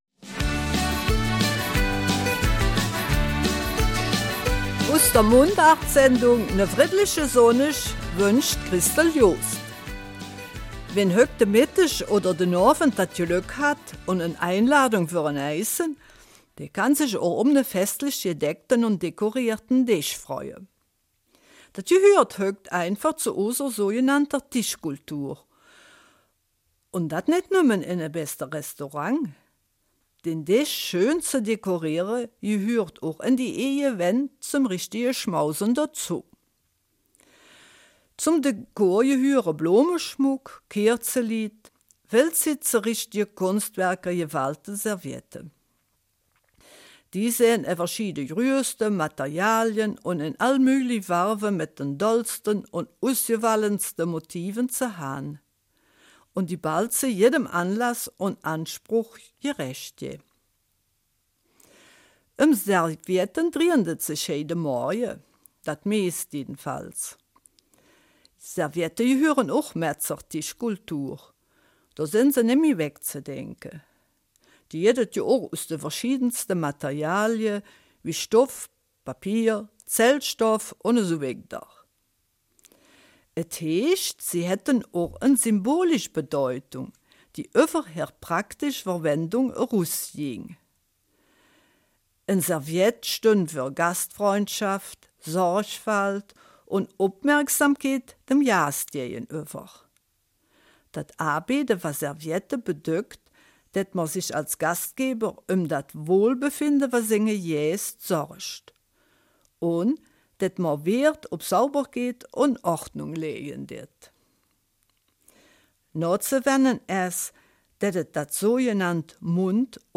Eifeler Mundart: Rund um unsere Tischkultur